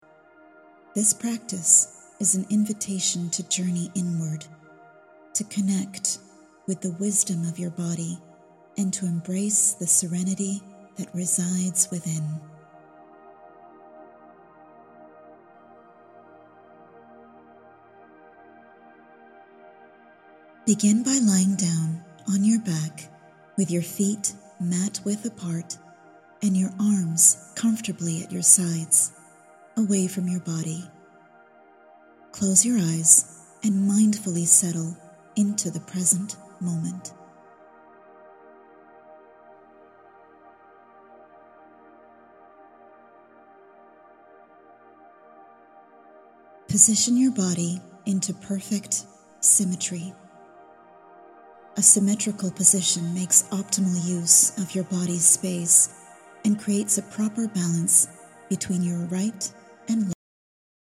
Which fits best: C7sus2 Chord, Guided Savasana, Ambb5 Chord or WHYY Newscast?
Guided Savasana